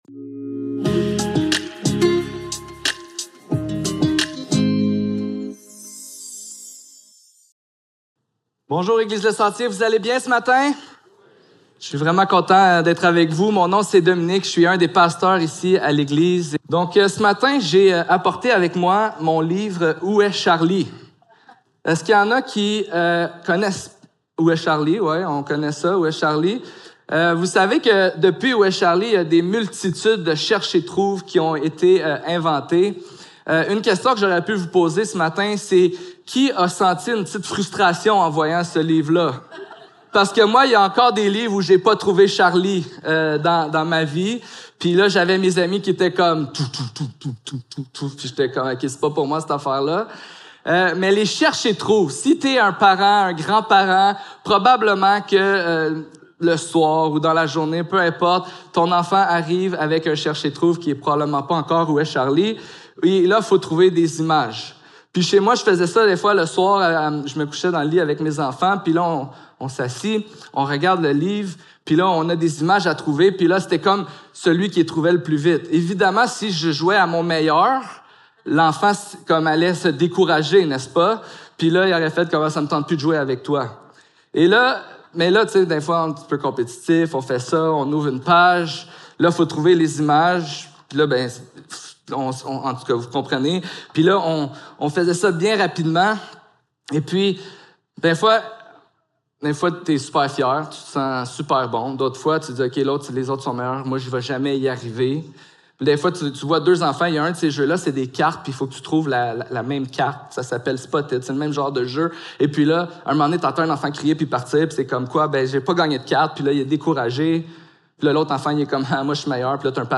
Marc 1.14-20 Service Type: Célébration dimanche matin Description